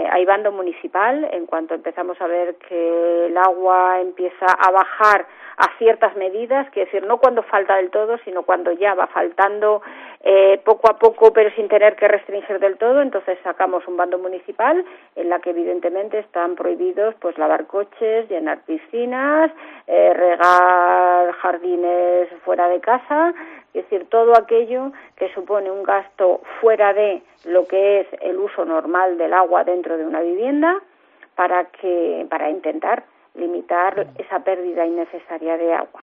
María Soledad Álvarez, alcaldesa de Mogarraz, opina sobre la sequía en el pueblo